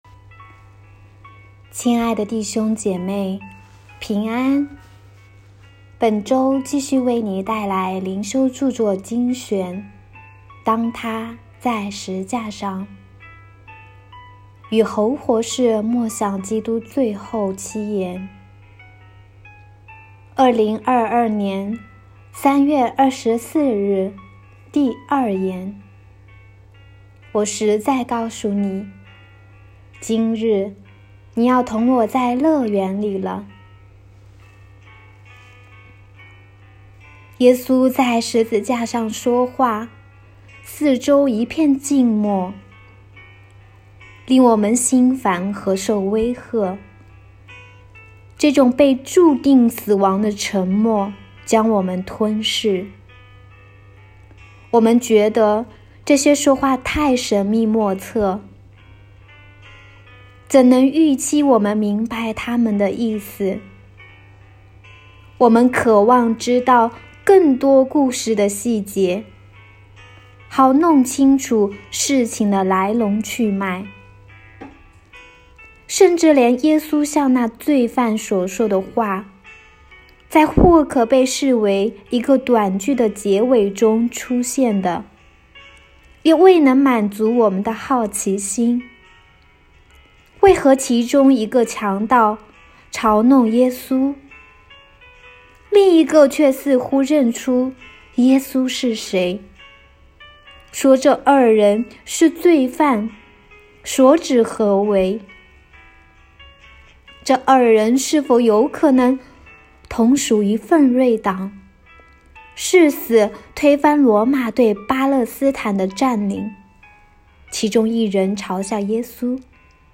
书籍朗读-音频： /wp-content/uploads/2022/04/当他在十架上-第二言.m4a 书籍：《当他在十架上-与侯活士默想基督最后七言》 内容简介： 本书带领读者默想十架七言。